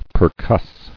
[per·cuss]